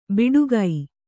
♪ biḍugai